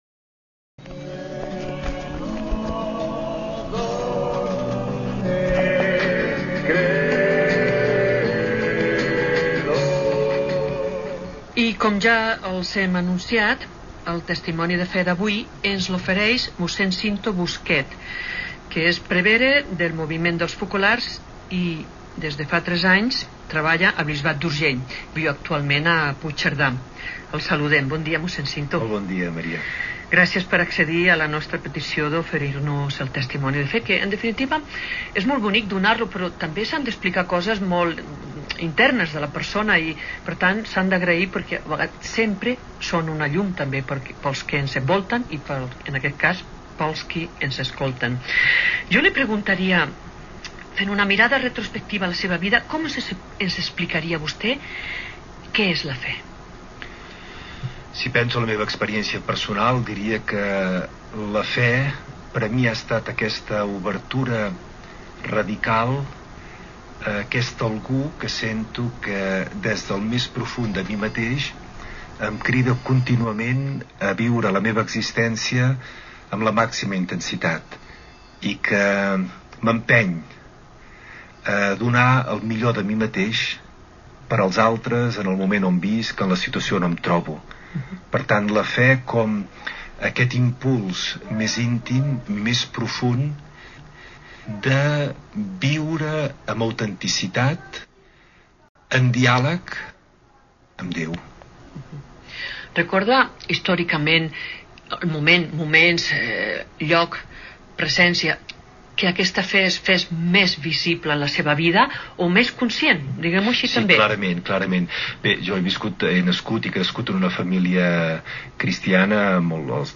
Gènere radiofònic Religió